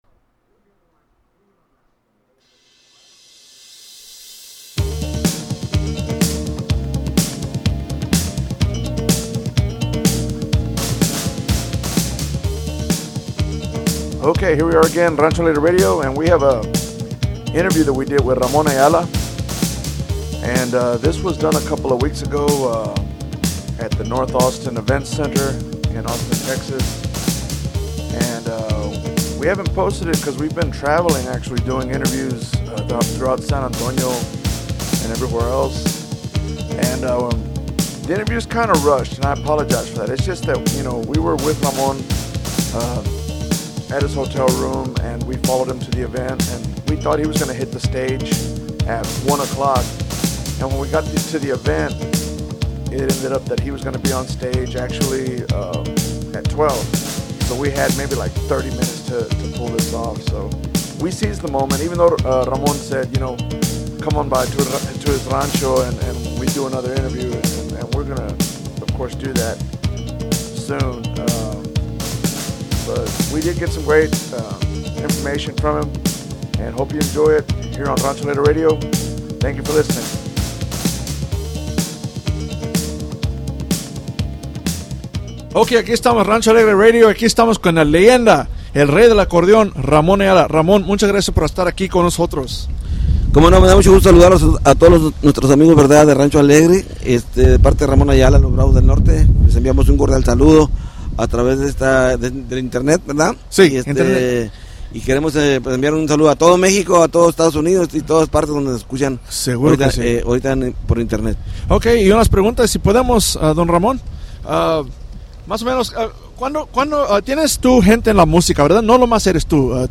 Rancho Alegre Interview - Ramon Ayala